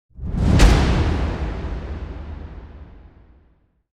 Fast Swoosh With Impact Hit Sound Effect
Description: Fast swoosh with Impact hit sound effect. Perfect for intros or scene transitions, especially for emphasizing quick movements or adding dynamic energy.
Scary sounds.
Fast-swoosh-with-impact-hit-sound-effect.mp3